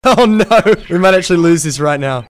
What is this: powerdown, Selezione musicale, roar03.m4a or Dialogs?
powerdown